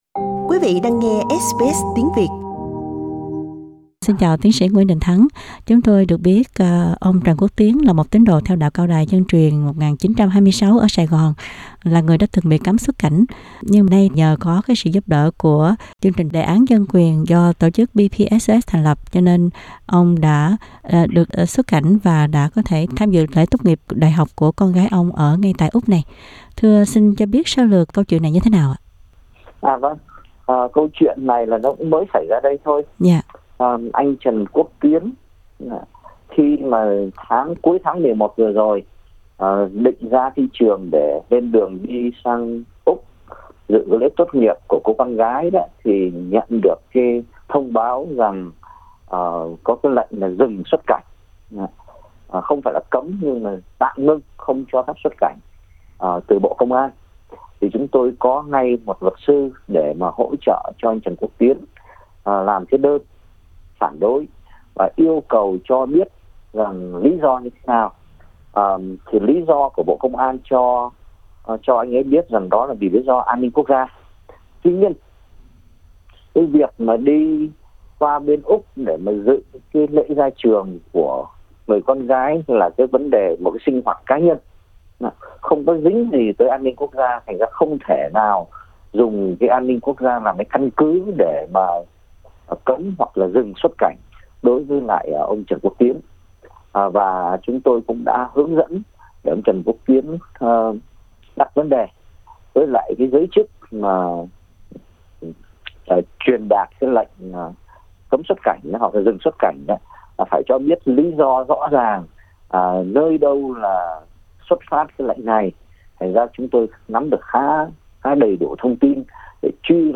Phỏng vấn